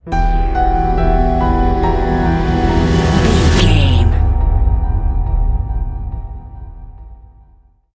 Aeolian/Minor
piano
synthesiser
tension
ominous
dark
suspense
haunting
creepy
spooky